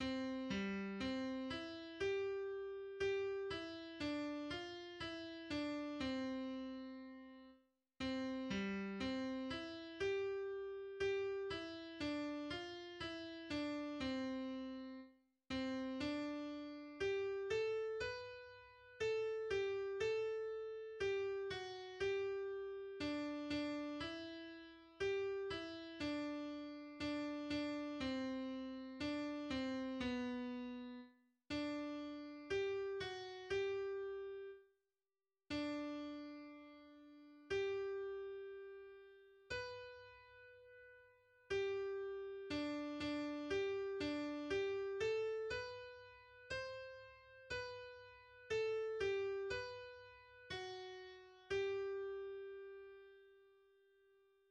\time 2/4
\key c \major